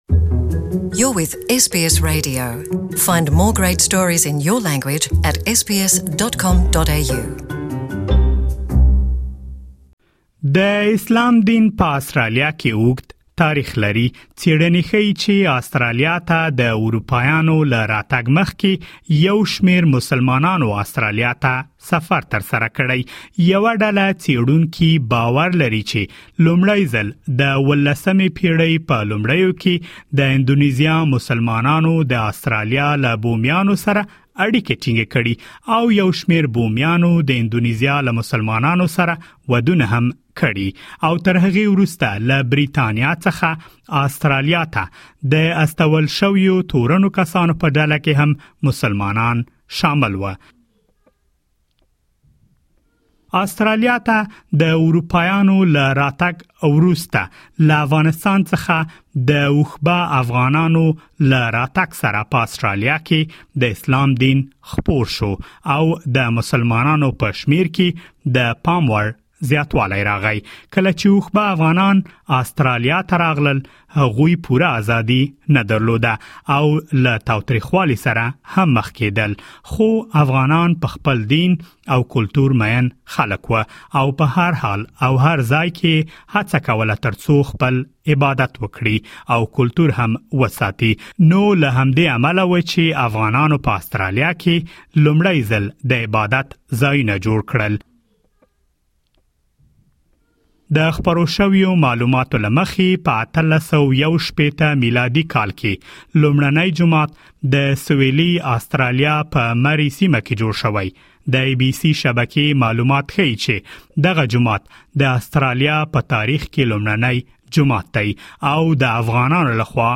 The Great Mosque of Adelaide was built in 1888 by the descendants of the Afghan. Please listen to the full report in Pashto language.